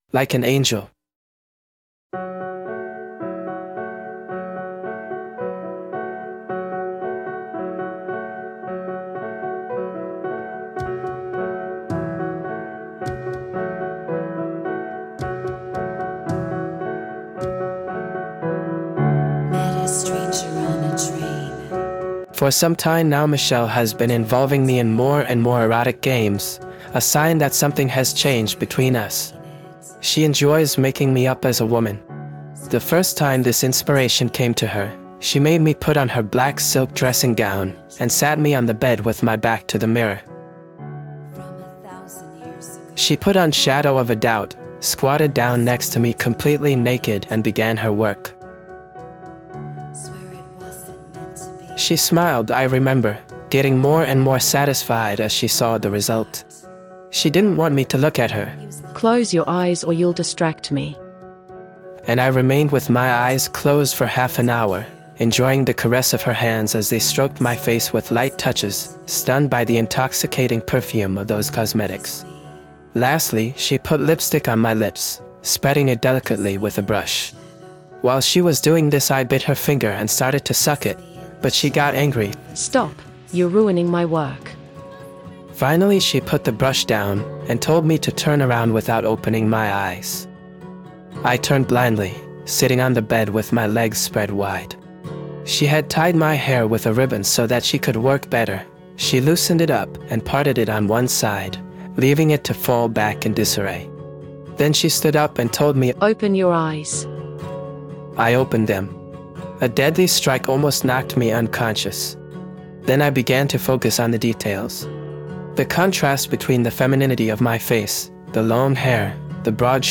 Covers of the following songs can be heard throughout the episode: Sonic Youth's "Shadow of a doubt" (cover by Black Tape For A Blue Girl), The Beatles' "Michelle" and Elliott Smith's "Between the bars".